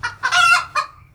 alarm.wav